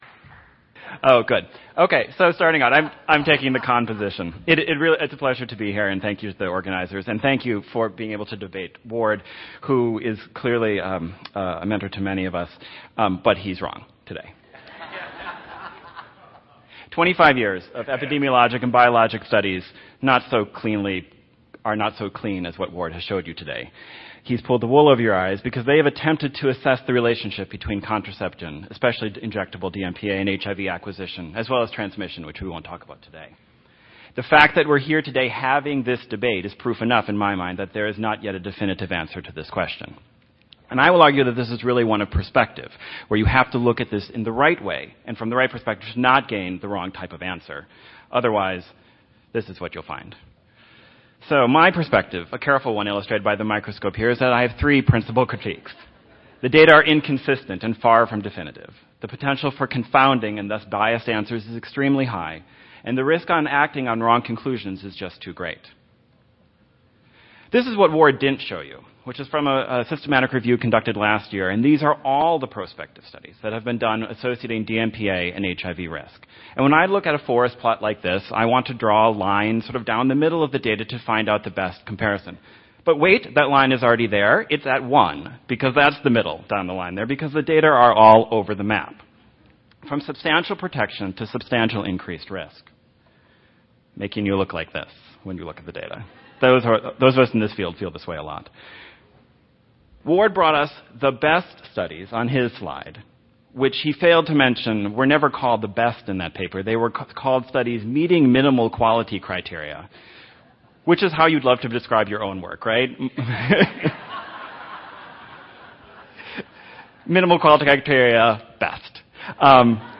36004 Debate 2: Pro